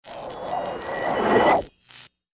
SA_buzz_word.ogg